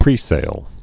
(prēsāl)